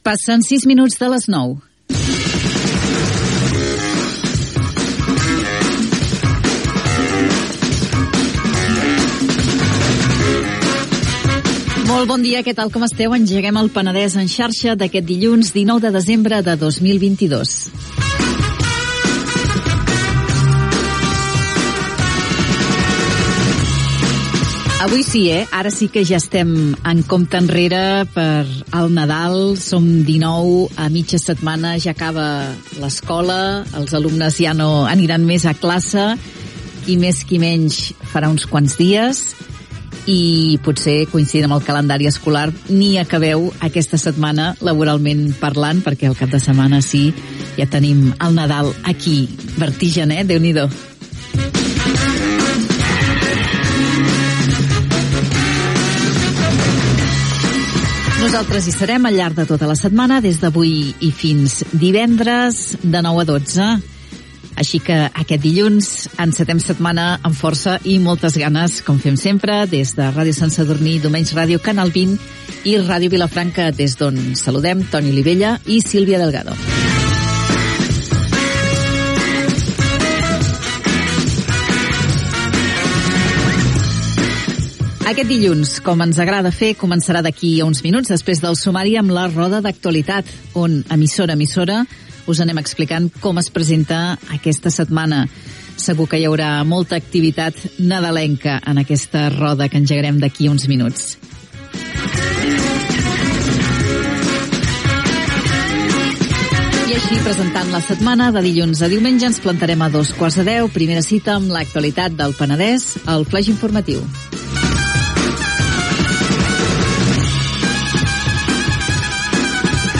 Info-entreteniment
Magazín emès per Ràdio Sant Sadurní, Domenys Ràdio, Canal 20 i Ràdio Vilafranca.